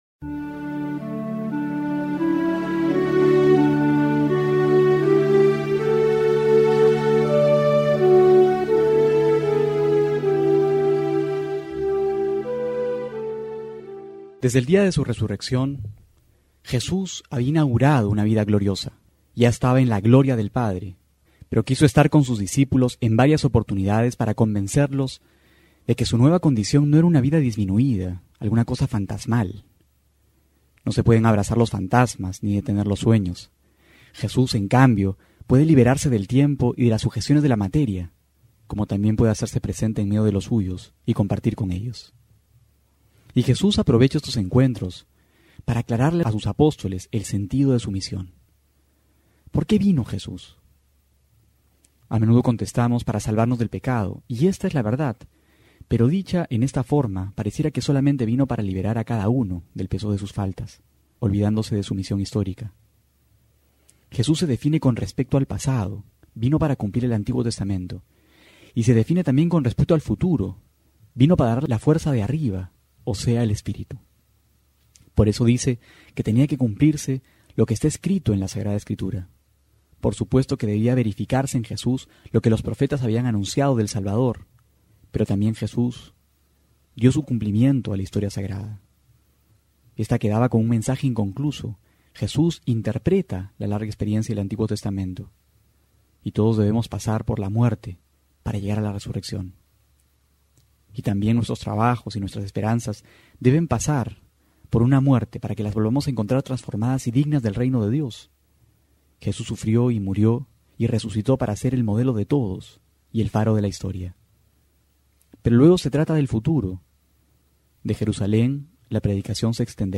Homilía para hoy: Lucas 24,35-48
abril12-12homilia.mp3